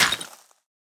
Minecraft Version Minecraft Version latest Latest Release | Latest Snapshot latest / assets / minecraft / sounds / block / suspicious_gravel / break5.ogg Compare With Compare With Latest Release | Latest Snapshot